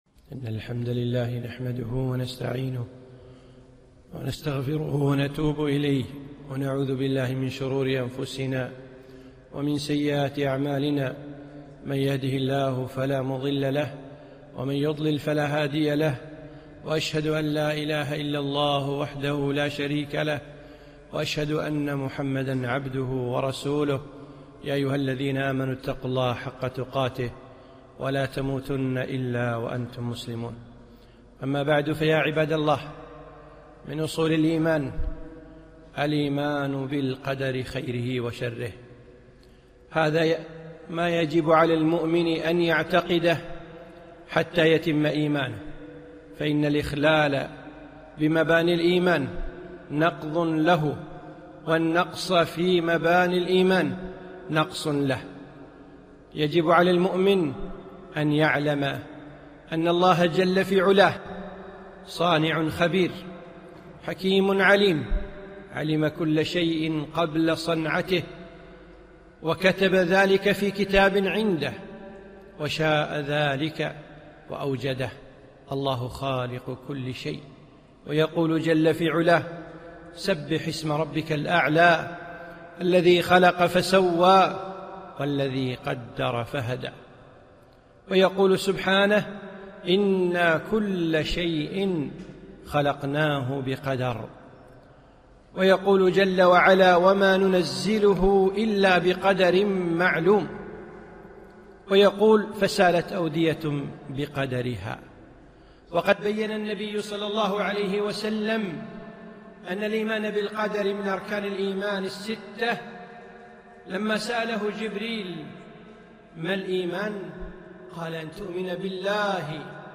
خطبة - الإِيمَانُ بِالْقَدَرِ